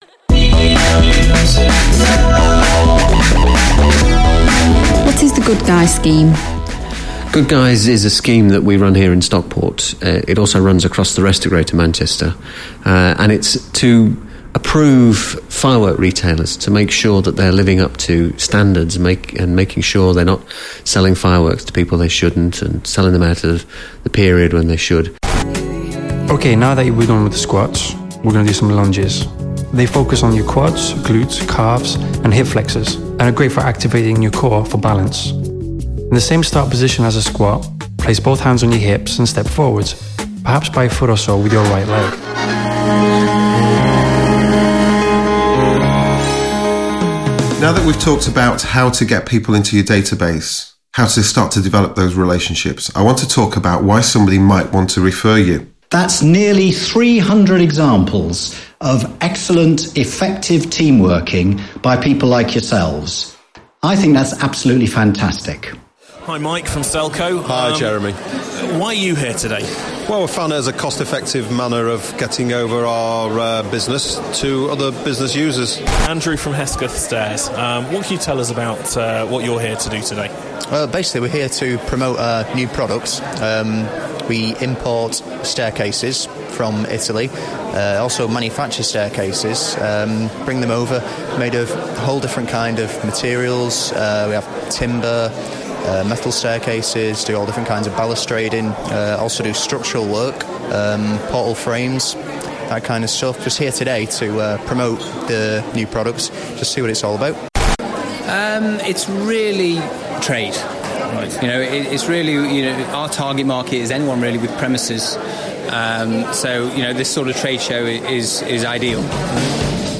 Here are examples of audio I have recorded on location, edited, and produced.
Location_Podcast_Demo_2.mp3